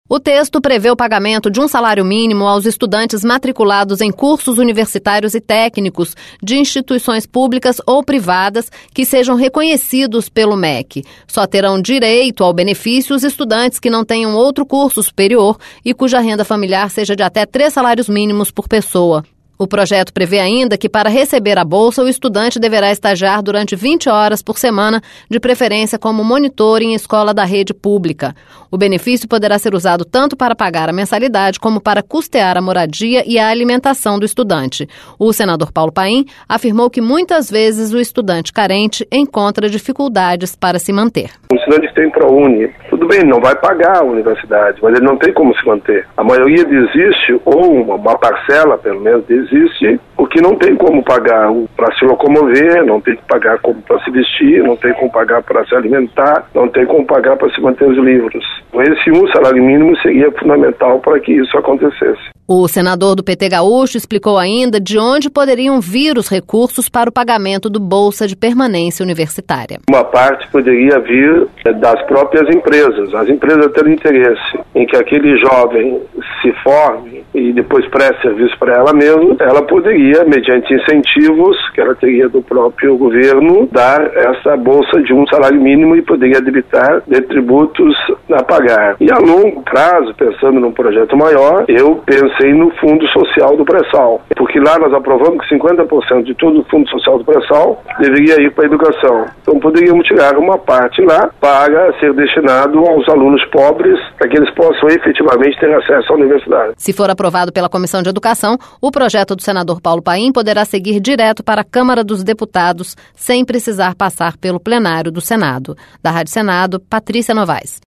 O senador Paulo Paim afirmou que muitas vezes o estudante carente encontra dificuldades para se manter.